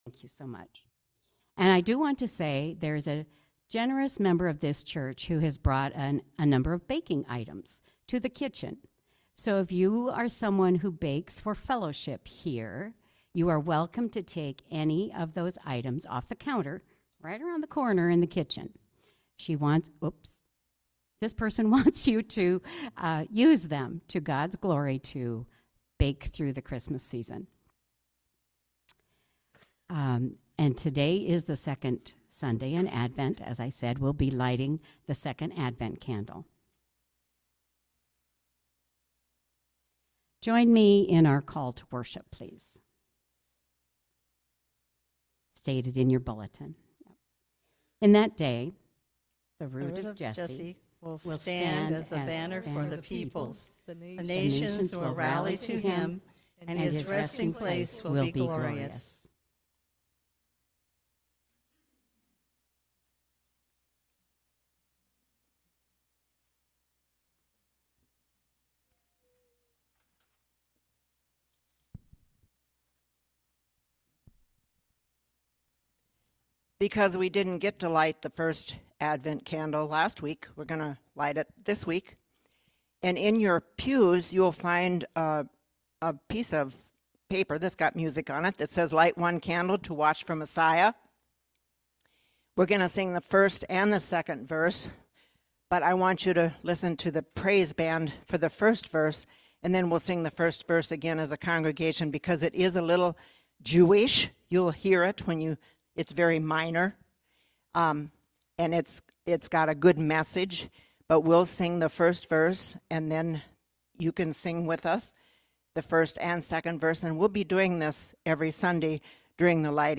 Worship-December-7-2025-Voice-Only.mp3